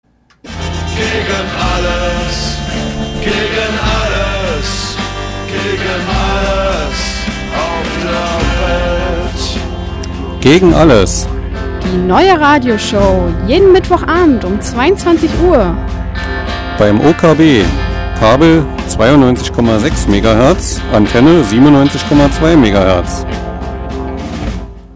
Werbejingle zur alten Sendung!